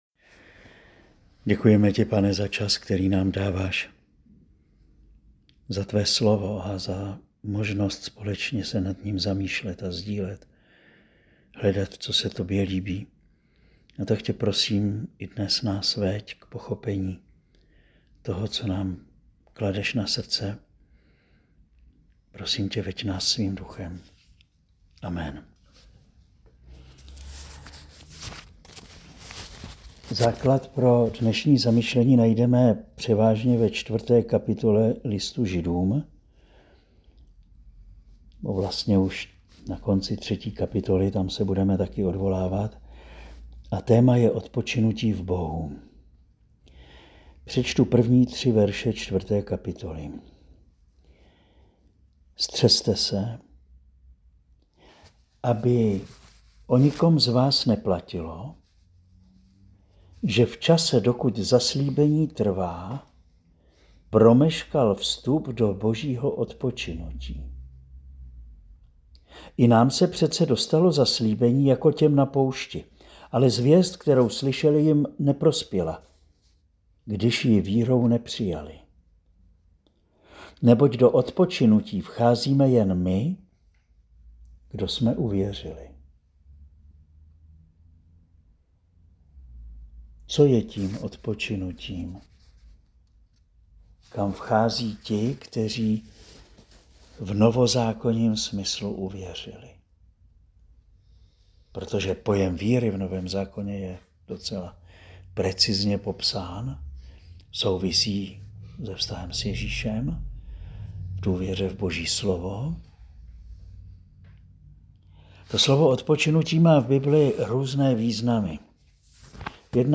Přednáška zazněla dne 9. 8. 2025